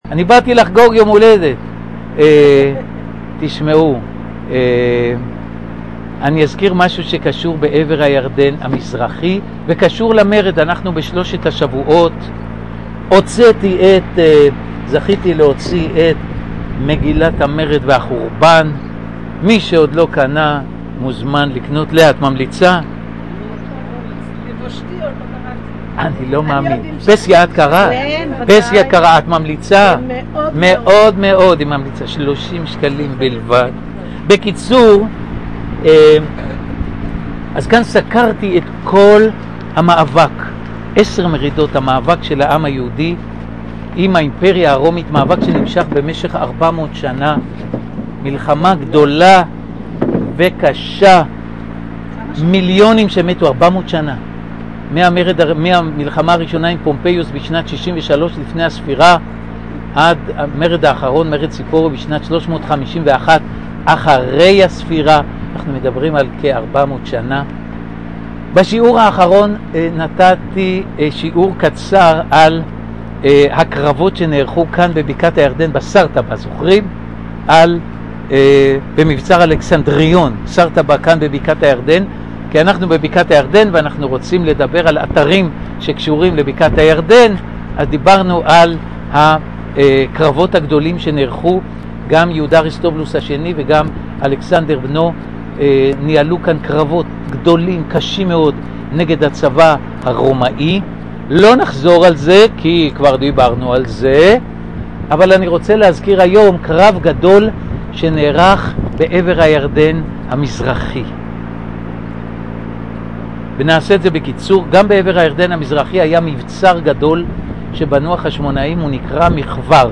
חוגגים ט"ז שנים לבית חגלה